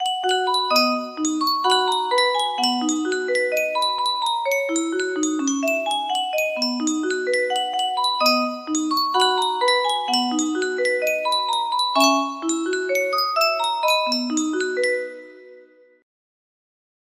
Yunsheng Music Box - How Great Thou Art Y219 music box melody
Full range 60